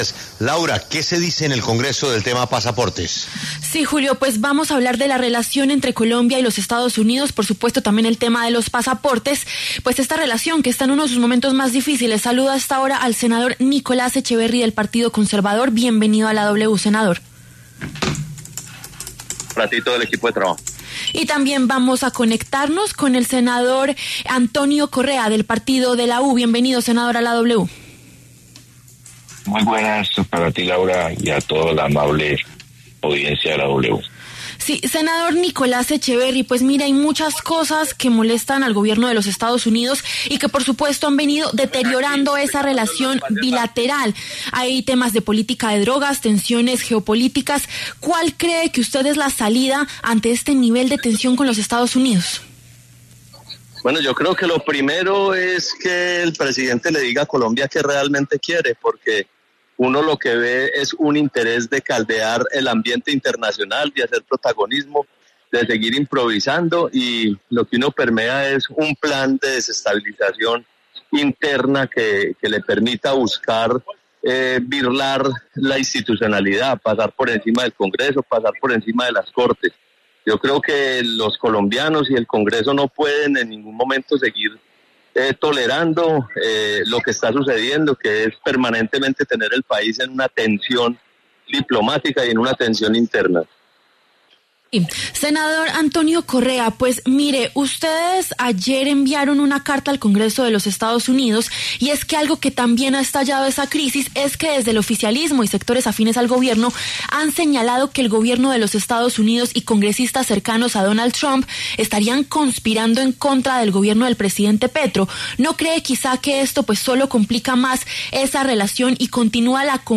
Los senadores Nicolás Echeverri, del Partido Conservador, y Antonio Correa, del Partido de la U, pasaron por los micrófonos de La W.